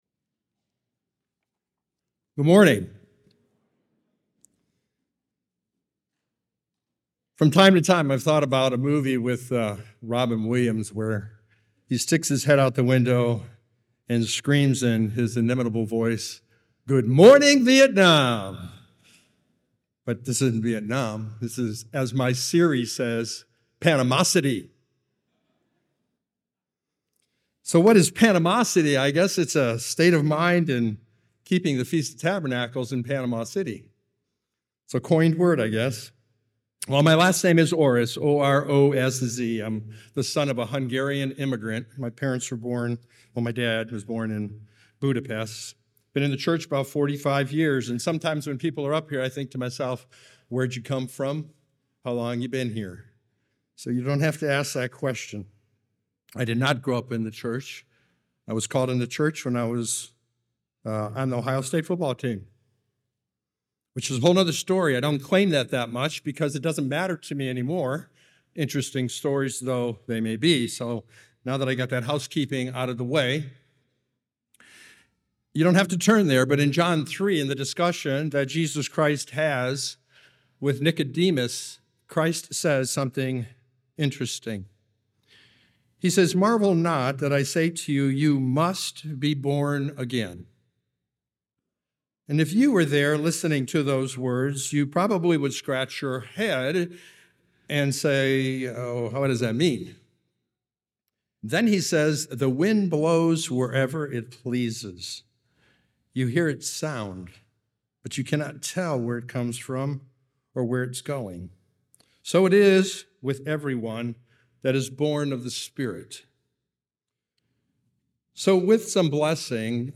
This sermon was given at the Panama City Beach, Florida 2023 Feast site.